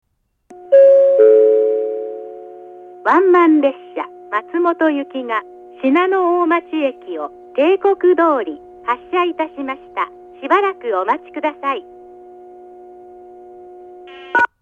発車ベルはありませんが、接近放送があります。
この駅の放送はどちらのホームに入線する場合でも上り列車は１番線のスピーカー、下り列車は２番線のスピーカーから放送が流れます。
１番線上り信濃大町駅発車案内放送 ワンマン松本行の放送です。